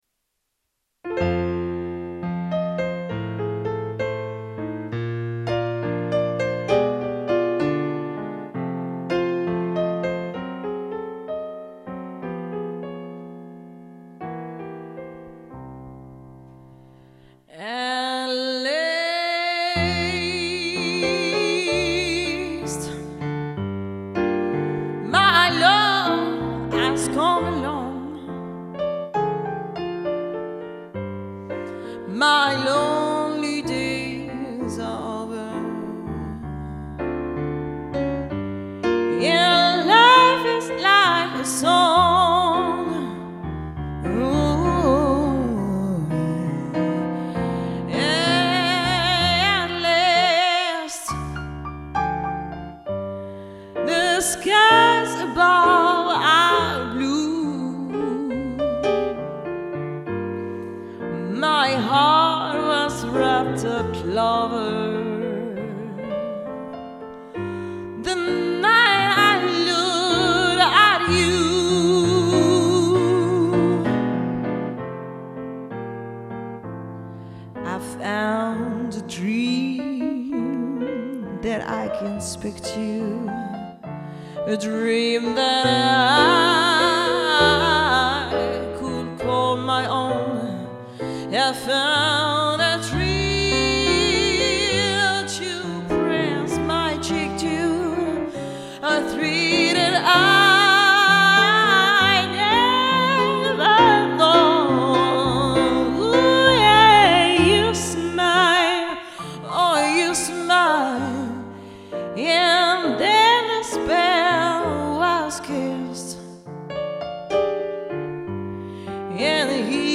en concert
violon, chant
piano, orgue
basse, chant, banjo, harmonica
batterie, vibraphone